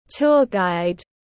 Hướng dẫn viên du lịch tiếng anh gọi là tour guide, phiên âm tiếng anh đọc là /tʊr ɡaɪd/.
Tour guide /tʊr ɡaɪd/
Tour-guide.mp3